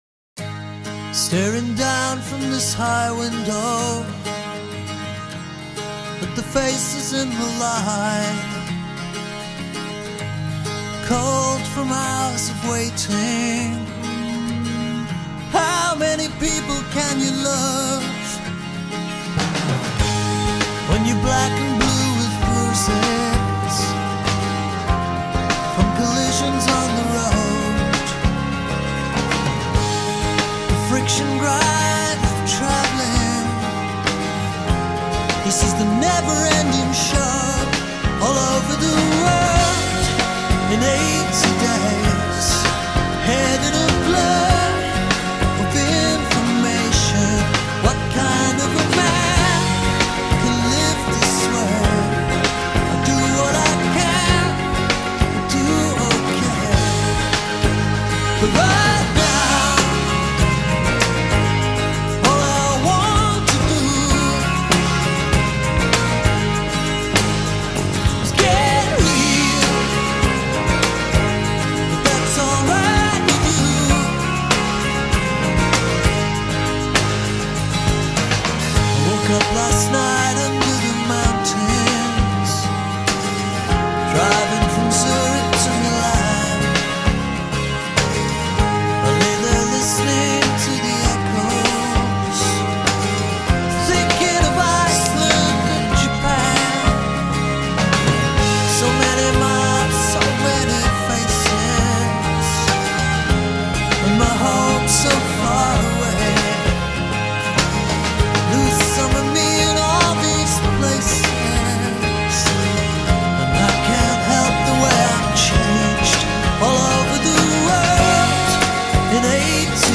British progressive rockers
beautifully-recorded and mellifluous sounds